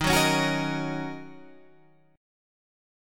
D# Minor 7th